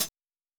Closed Hats
TC3Hat6.wav